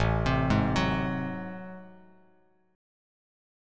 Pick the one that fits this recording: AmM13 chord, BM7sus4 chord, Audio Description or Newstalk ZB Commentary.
AmM13 chord